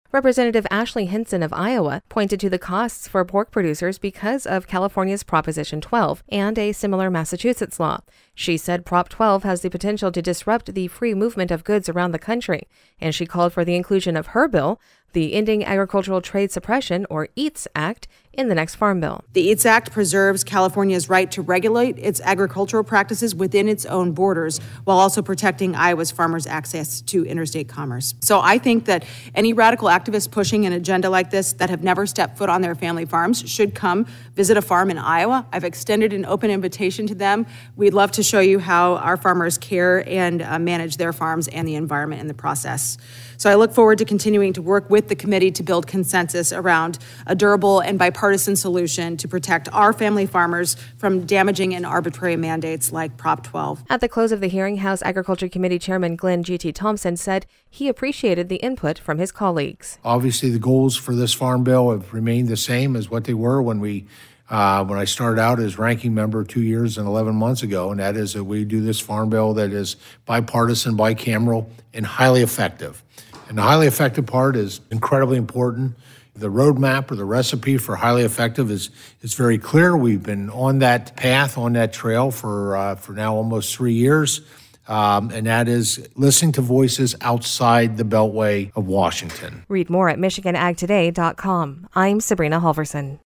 Rep. Ashley Hinson (R-IA-02), speaking before the House Ag Chairman and Ranking Member during the “Member Day” hearing on Wednesday, Dec. 6, 2023.